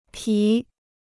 皮 (pí): leather; skin.